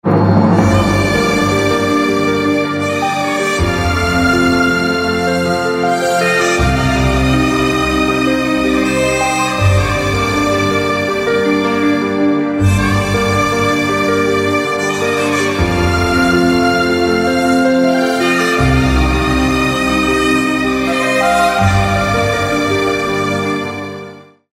زنگ موبایل (بی کلام) محزون و سوزناک